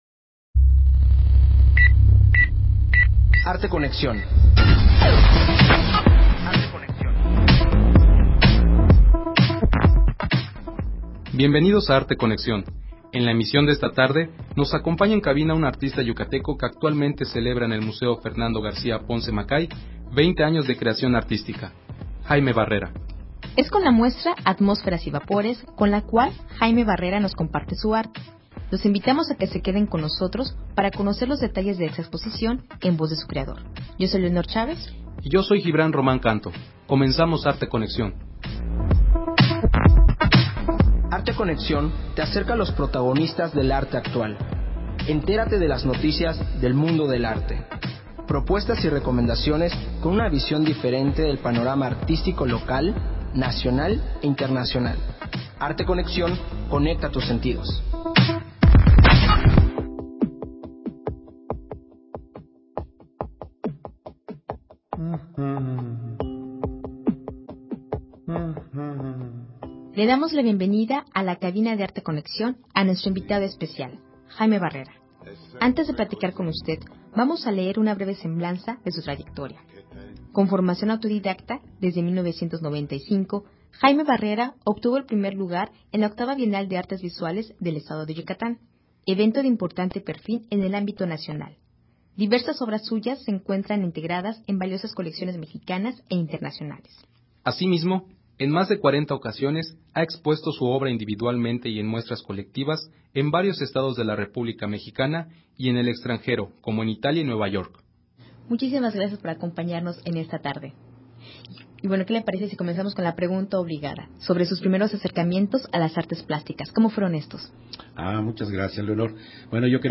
Emisión de Arte Conexión transmitida el 3 de marzo.